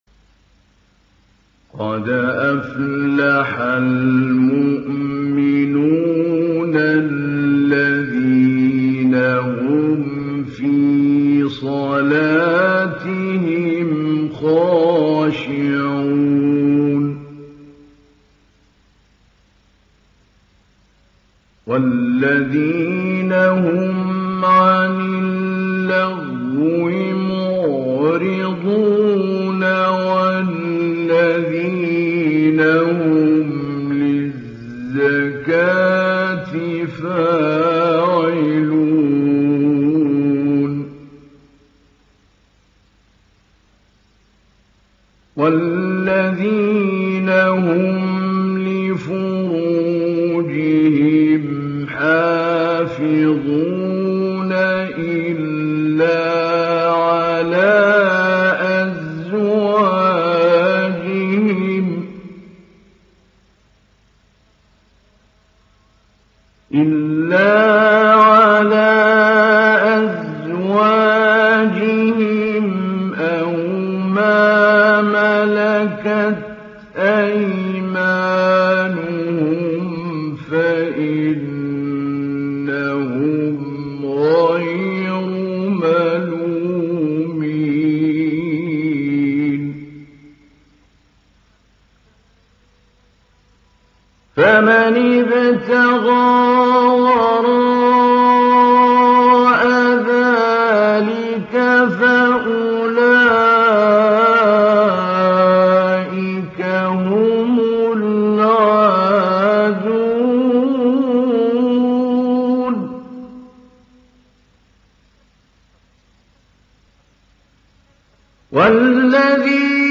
İndir Muminun Suresi Mahmoud Ali Albanna Mujawwad
Mujawwad